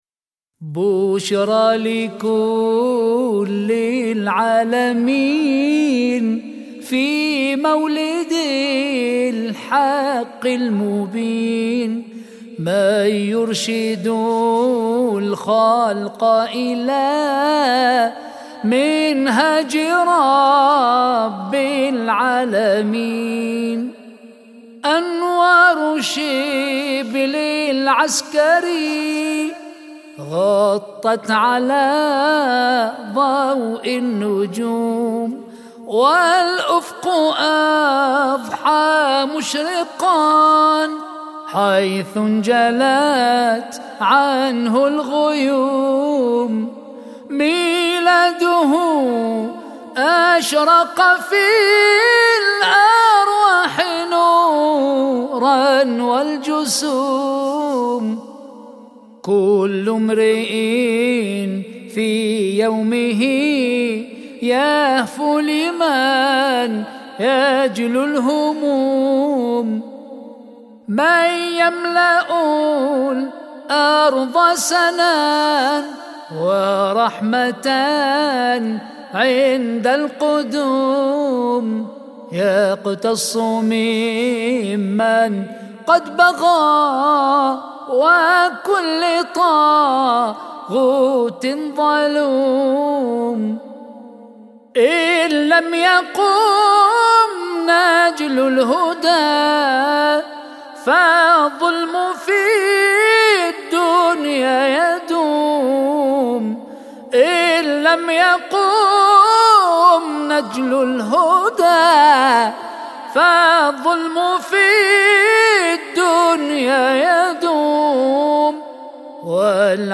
مدائح